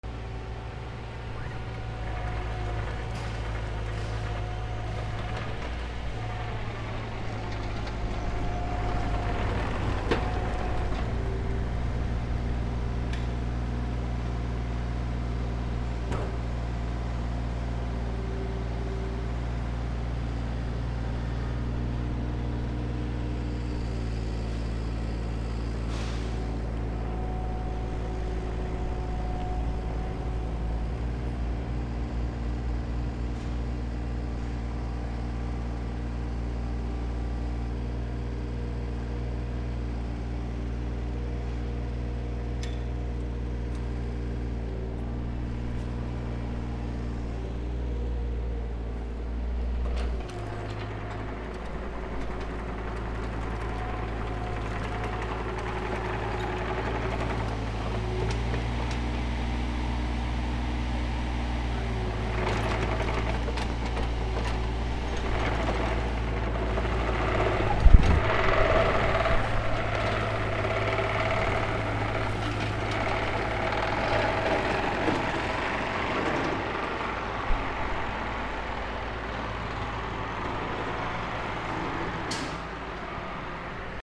/ C｜環境音(人工) / C-35 ｜工事現場
ショベルカー キャタピラあり
工事現場 D100